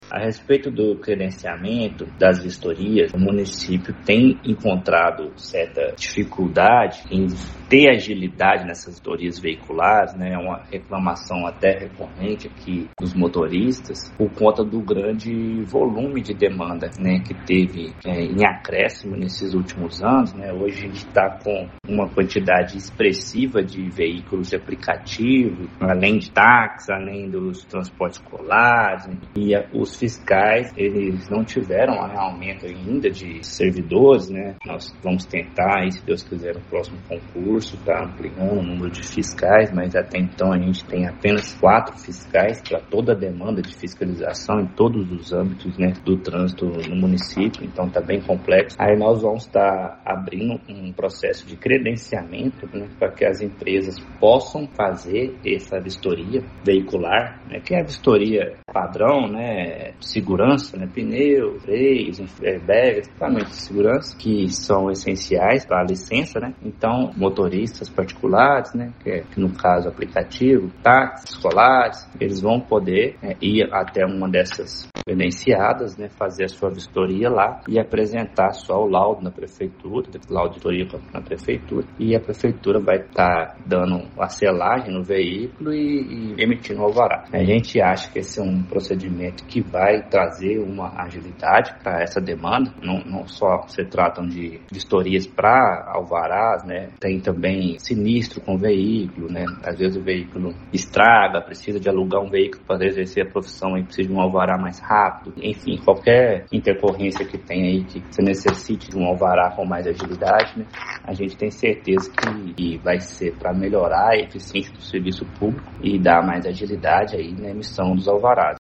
O secretário municipal de Desenvolvimento Urbano, Marcos Vinícius de Oliveira Santos, explicou as razões que levaram a gestão municipal a optar pelo credenciamento de empresas para a realização das inspeções veiculares: